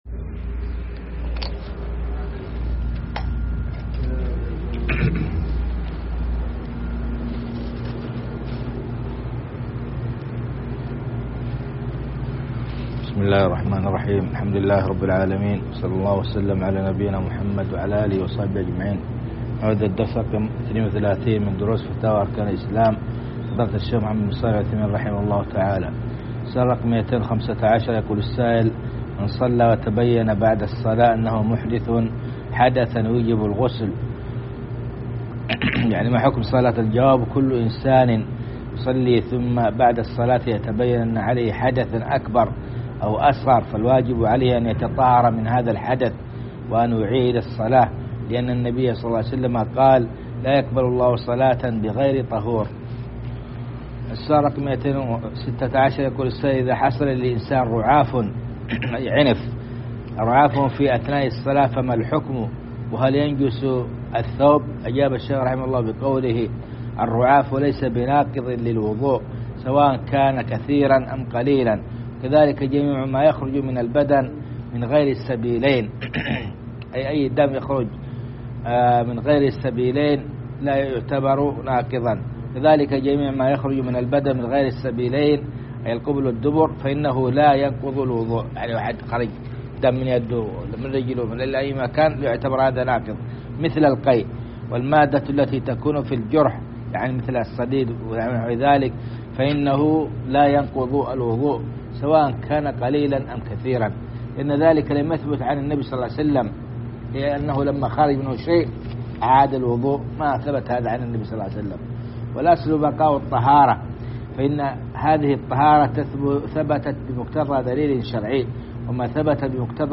شرح فتاوى أركان الإسلام الدرس 102 كتاب الصلاة 32